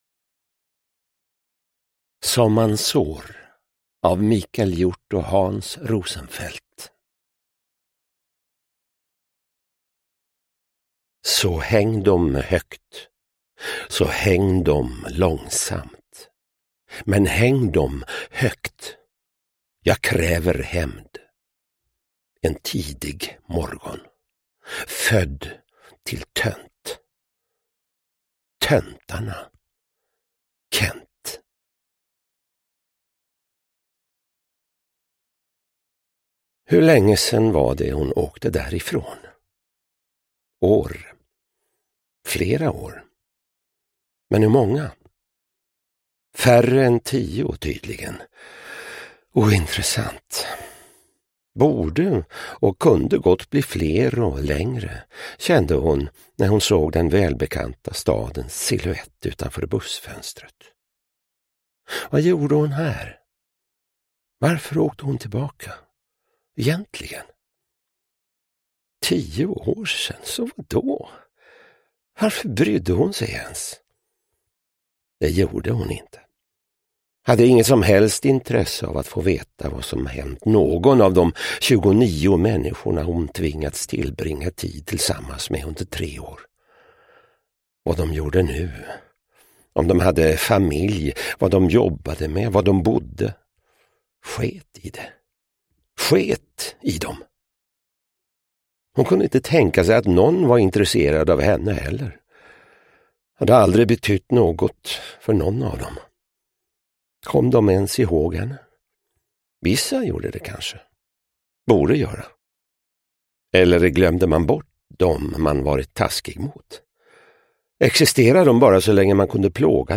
Som man sår (ljudbok) av Hans Rosenfeldt